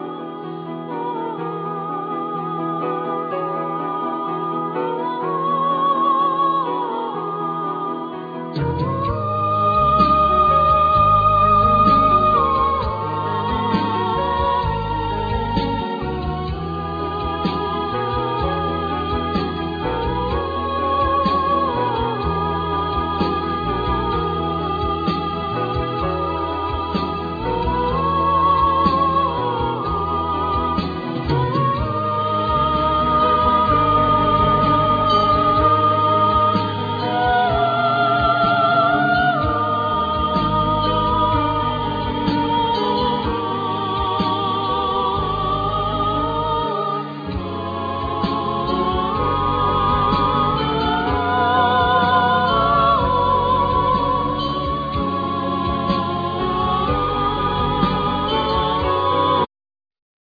Flute,EW5
Guitars
Cello
Keyboards,Bass,Percussions
Voices